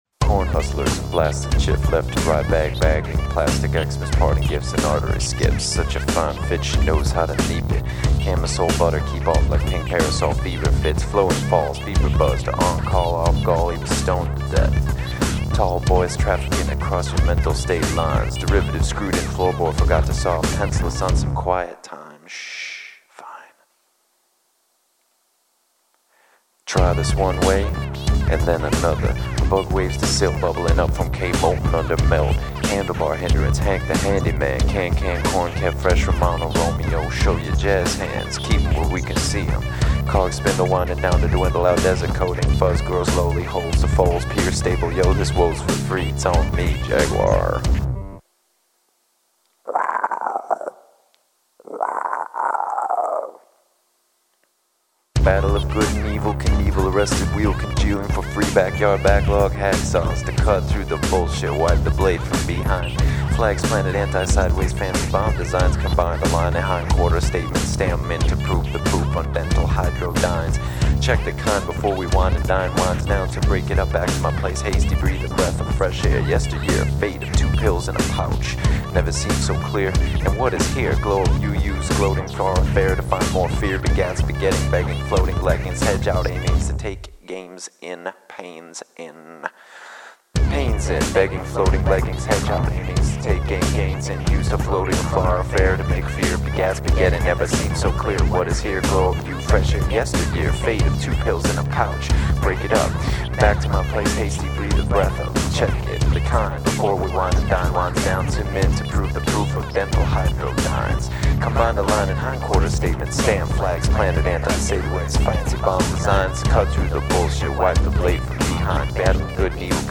recorded in the forest  february - april 2006